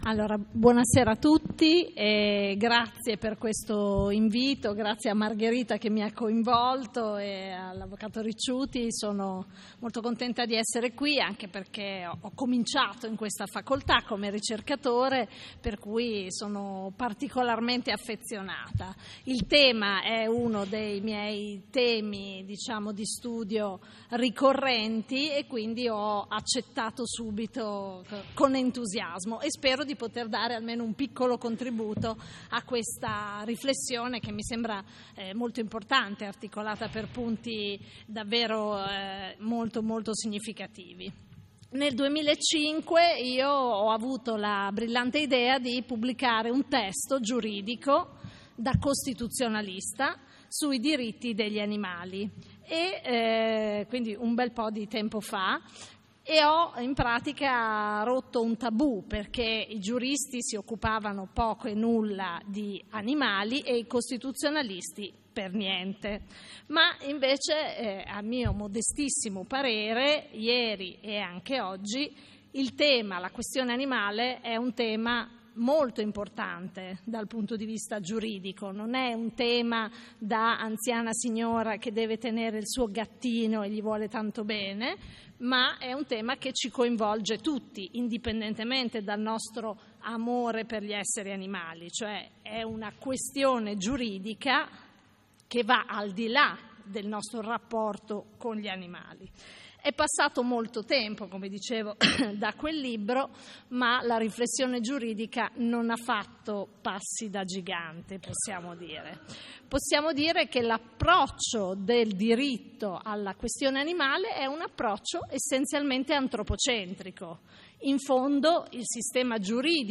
Convegno di studi organizzato da Animal Law Italia in collaborazione con SIUA – Scuola di formazione zooantropologica e con il patrocinio dell’Alma Mater Studiorum Università di Bologna, svoltosi presso la Sala Armi della Facoltà di Giurisprudenza dell’Università di Bologna.